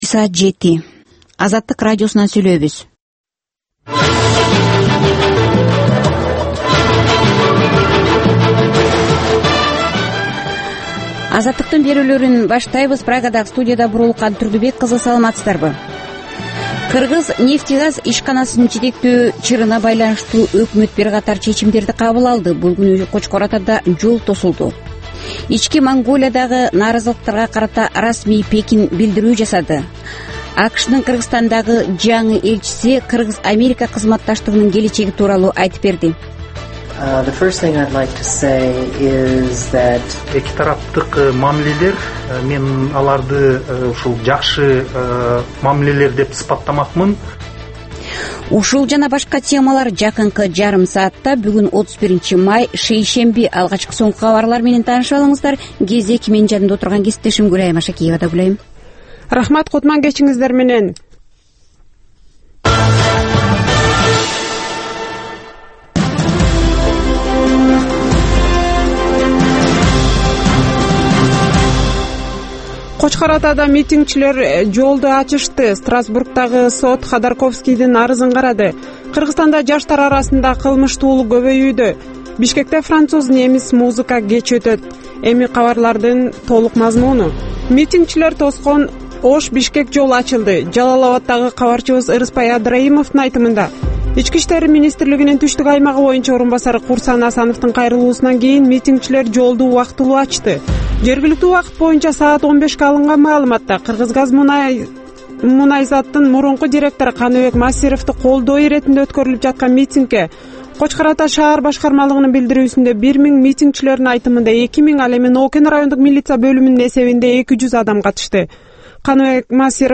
Кечки 7деги кабарлар